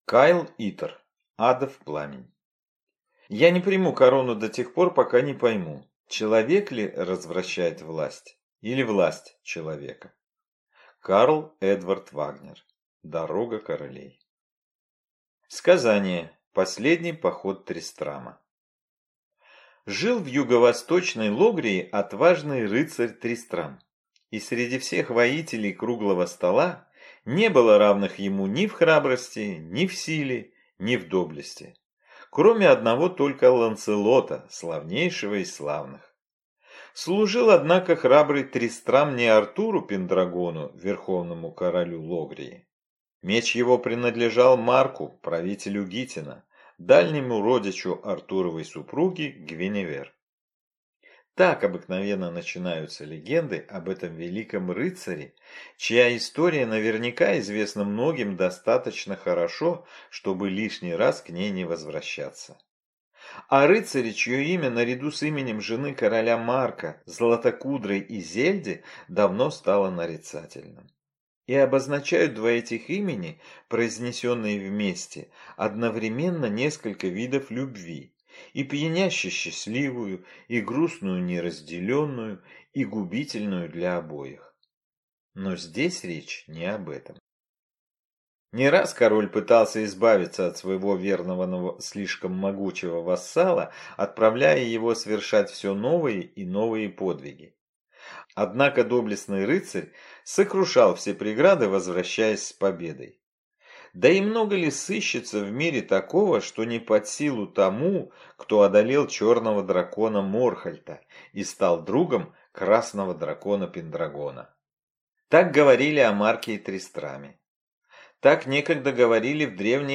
Аудиокнига Адов Пламень | Библиотека аудиокниг